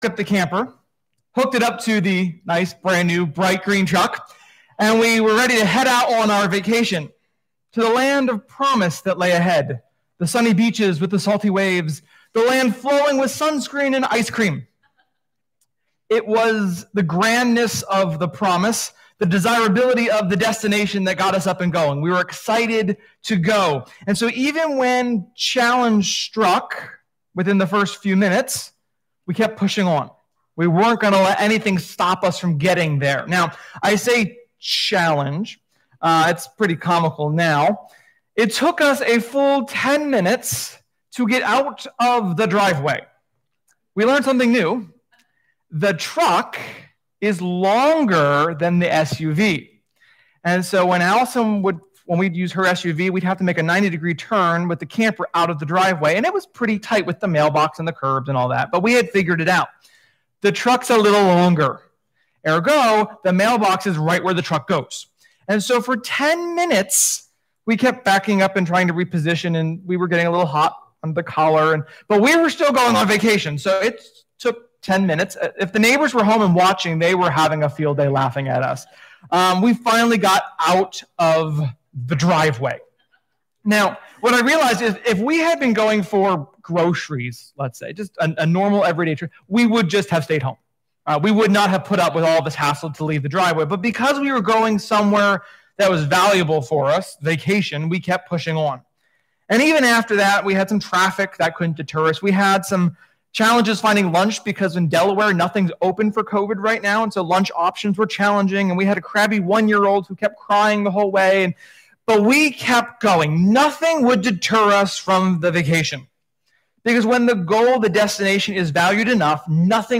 Sermon-9.6.20.mp3